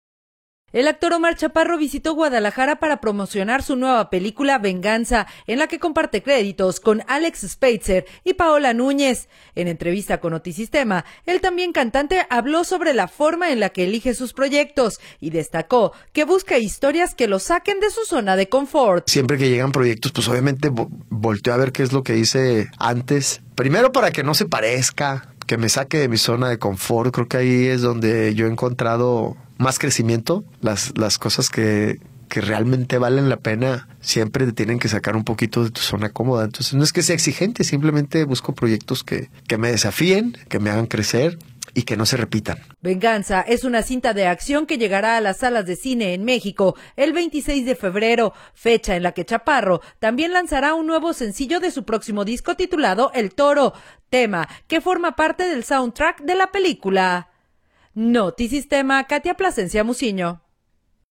El actor Omar Chaparro visitó Guadalajara para promocionar su nueva película “Venganza”, en la que comparte créditos con Alex Speitzer y Paola Núñez. En entrevista con Notisistema, el también cantante habló sobre la forma en la que elige sus proyectos y destacó […]